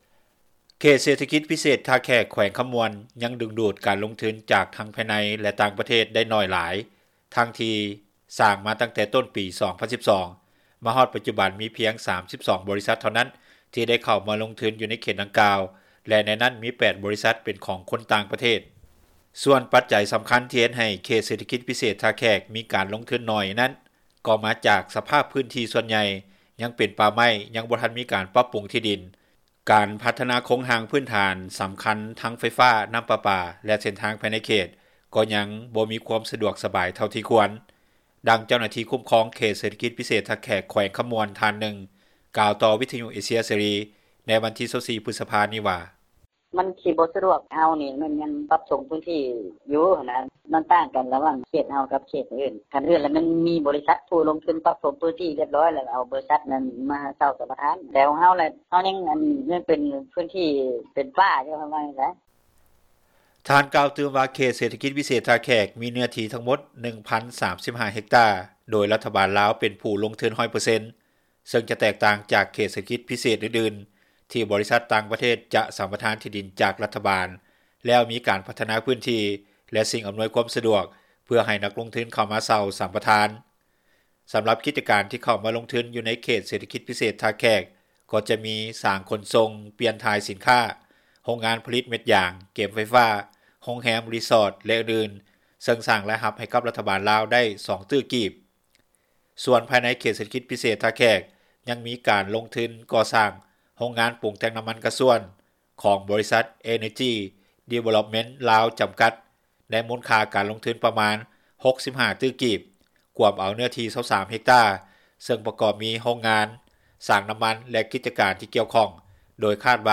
ດັ່ງຊາວບ້ານ ໄດ້ກ່າວຕໍ່ວິທຍຸ ເອເຊັຽ ເສຣີ ໃນມື້ດຽວກັນນີ້ວ່າ:
ດັ່ງຊາວບ້ານ ກ່າວໃນມື້ດຽວກັນວ່າ: